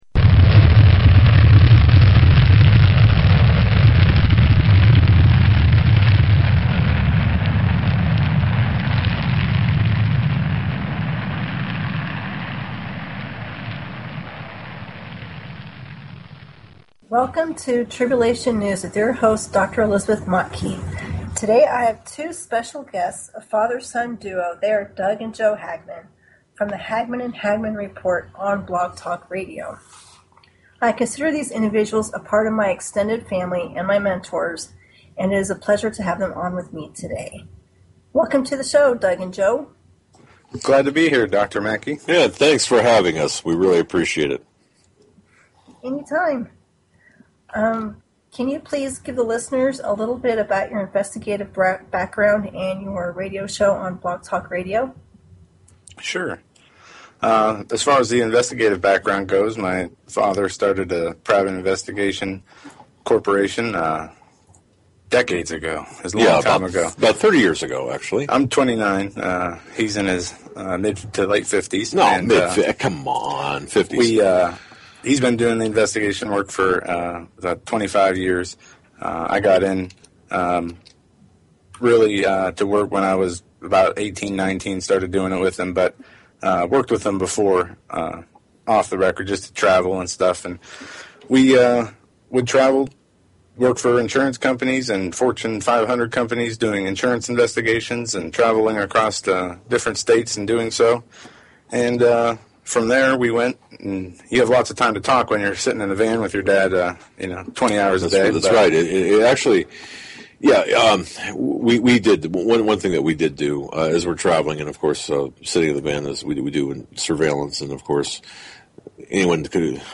Talk Show Episode, Audio Podcast, Tribulation_News and Courtesy of BBS Radio on , show guests , about , categorized as
Tribulation News is all about current events, and how Bible Prophecy is getting fulfilled. This broadcast will also include a Bible study, and it is an online ministry to reach as many people as possible during these end times.